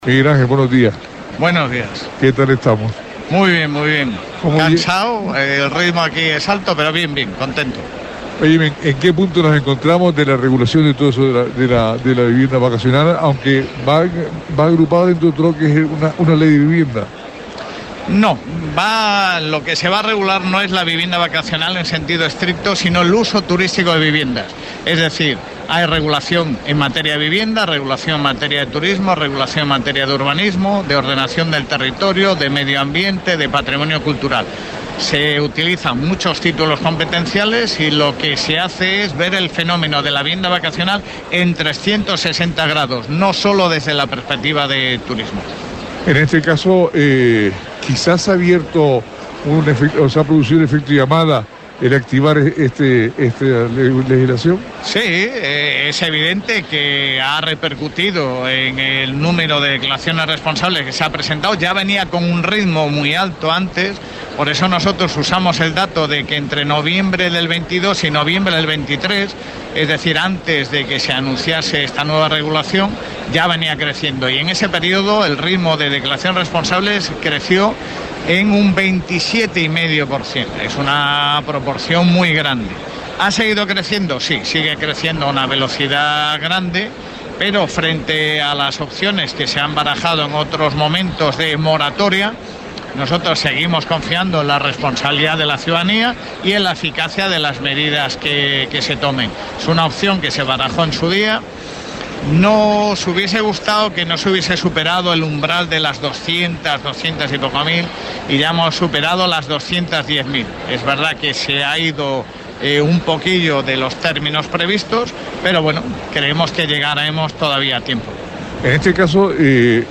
Nos encontramos, en la ITB, con Miguel Ángel Rodríguez, director general de Ordenación, Formación y Promoción Turística